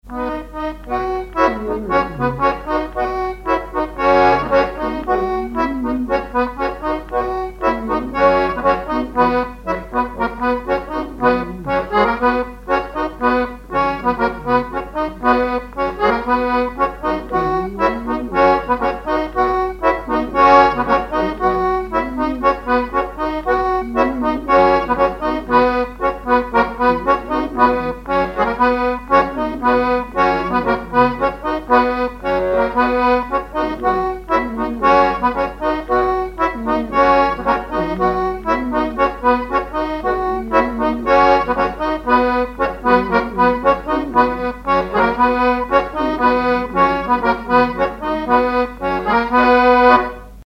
Divertissements d'adultes - Couplets à danser
branle : courante, maraîchine
Répertoire sur accordéon diatonique
Pièce musicale inédite